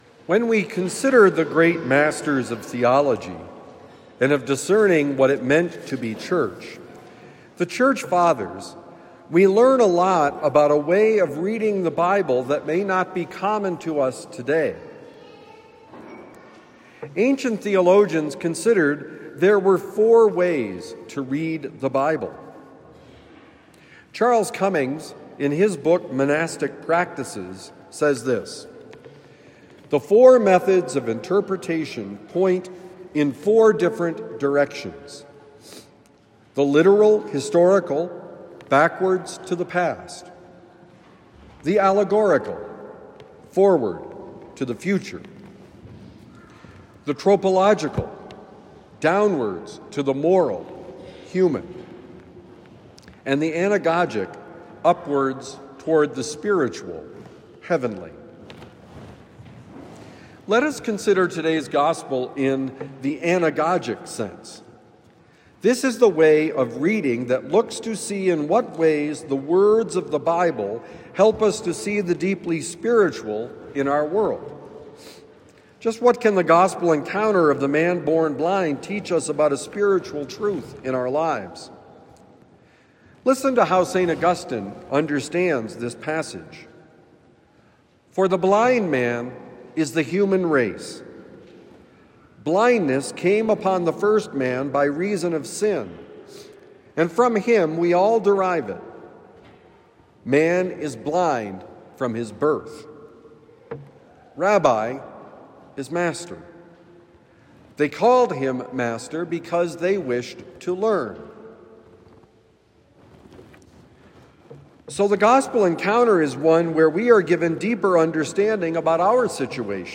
Jesus Saves: Homily for Sunday, March 15, 2026